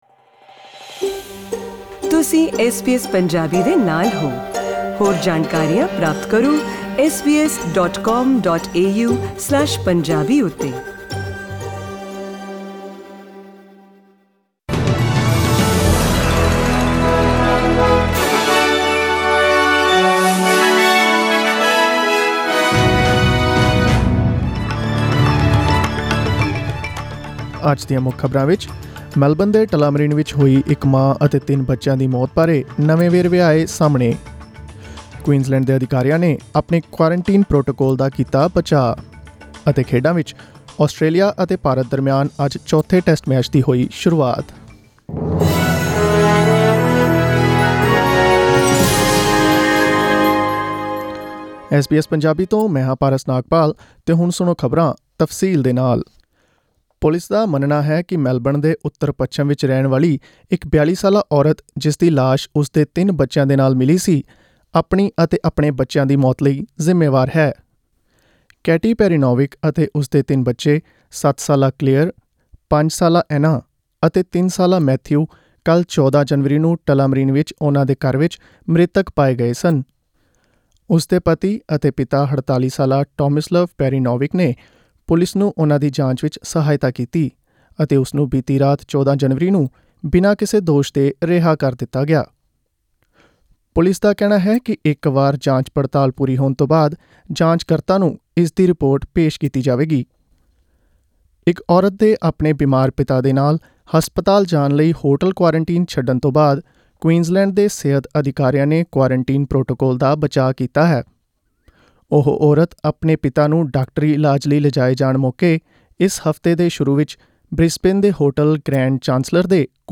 Australian News in Punjabi: 15 January 2021